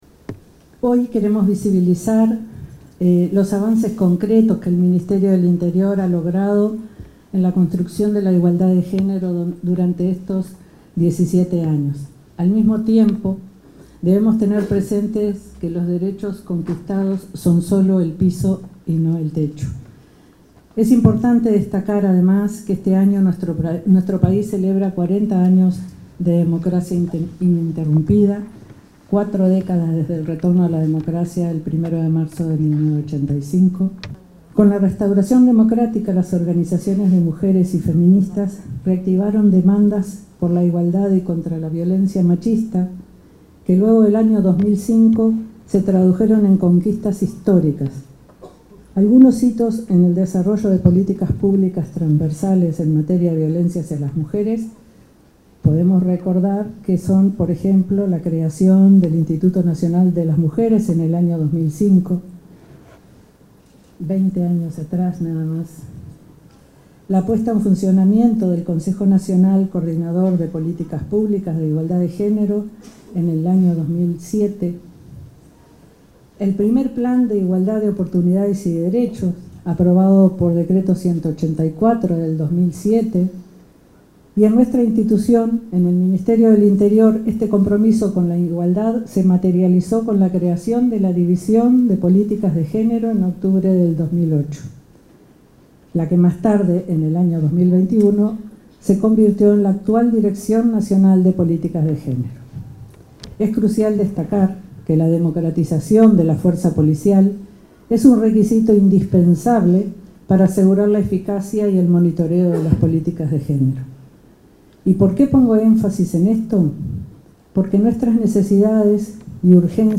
Palabras de autoridades en acto por el 17° aniversario de la institucionalidad de políticas de género en el Ministerio del Interior
La encargada de la Dirección Nacional de Políticas de Género, July Zabaleta, y el ministro del Interior, Carlos Negro, expusieron en el 17. °